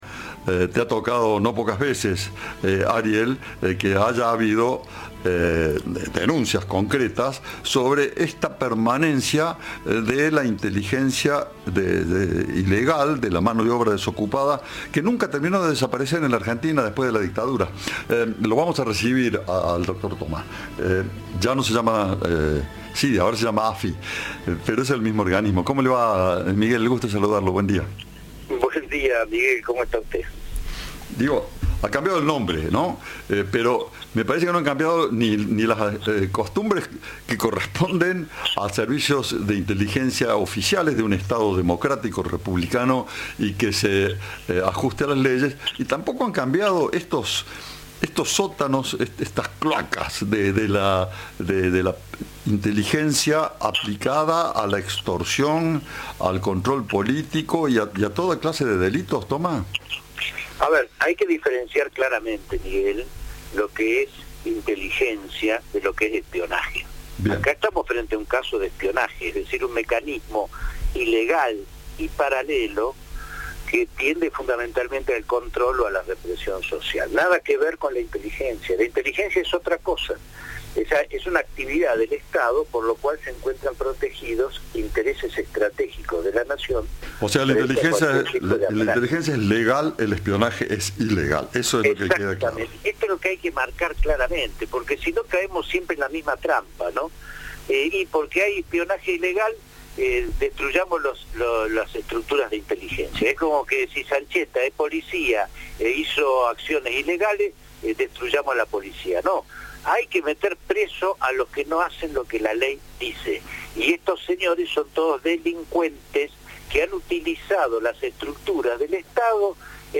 El exdirector de la SIDE (actual AFI) habló con Cadena 3 sobre el escándalo por la existencia de una organización que vulneró comunicaciones telefónicas.